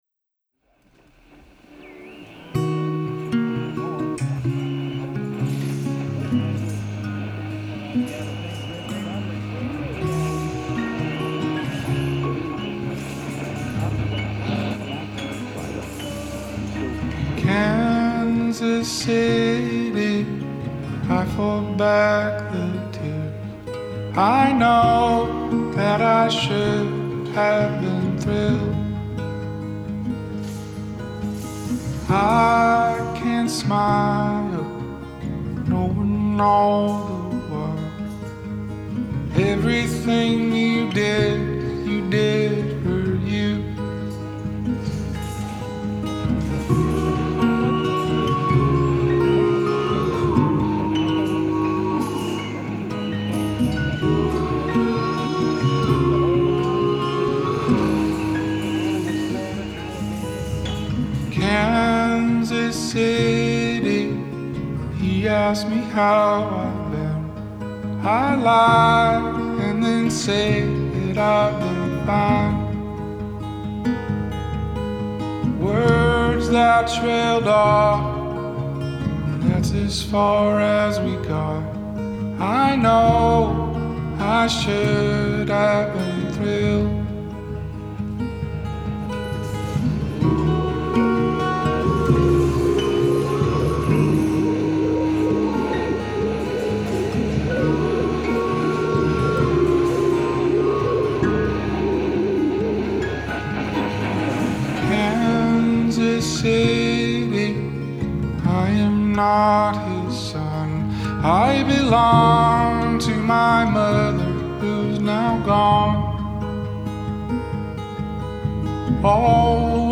There’s something so mournful about it.